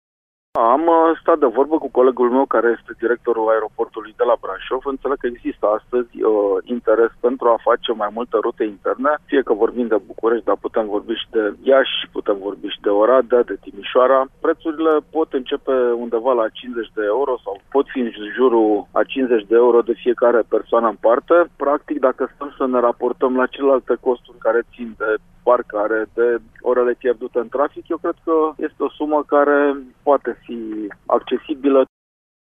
Președintele CJ Brașov, Adrian Veștea: